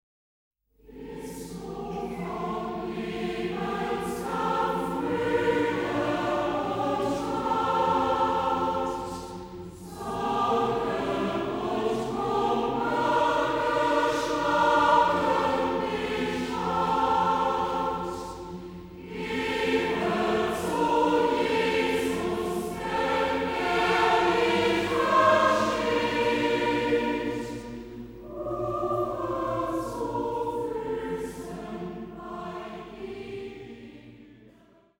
Instrumentalensemble, Orgel